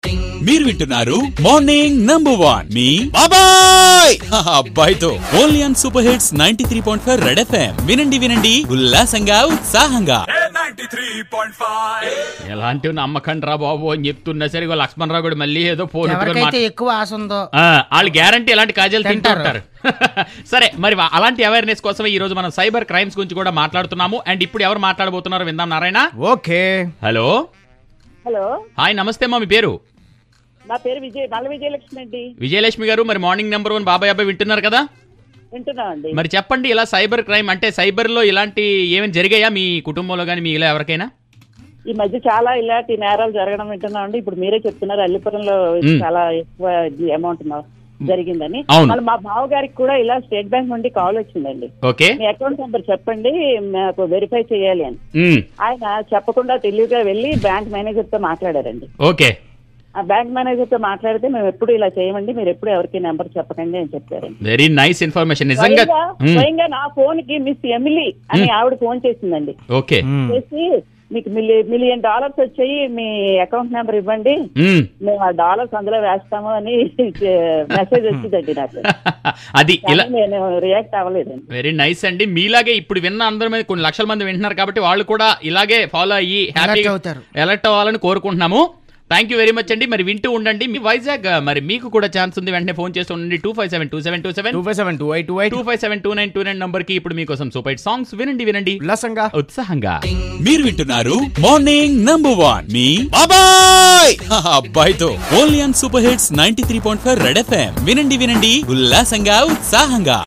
a vizagite sharing her cyber crime experience.